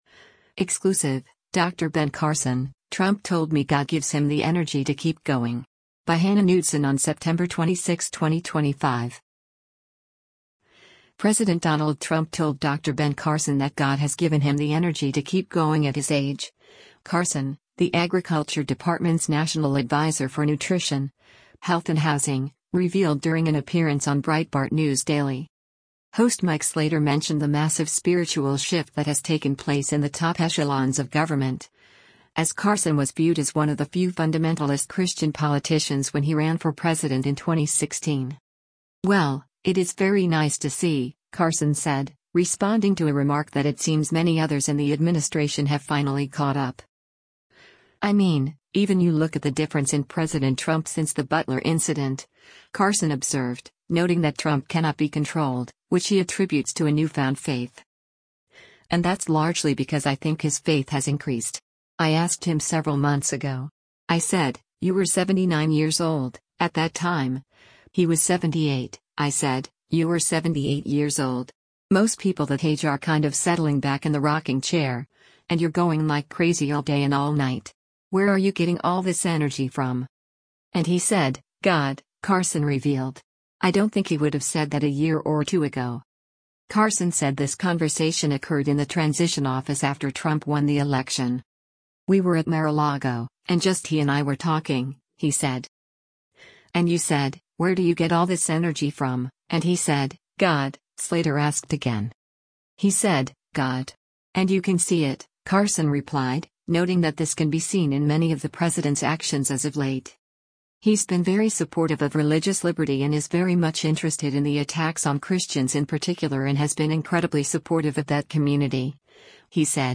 President Donald Trump told Dr. Ben Carson that God has given him the energy to keep going at his age, Carson — the Agriculture Department’s national adviser for nutrition, health and housing — revealed during an appearance on Breitbart News Daily.
Breitbart News Daily airs on SiriusXM Patriot 125 from 6:00 a.m. to 9:00 a.m. Eastern.